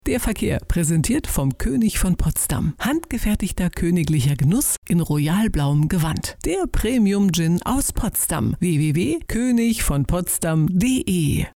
KÖNIG VON POTSDAM® präsentiert den Verkehr (Radio Spot)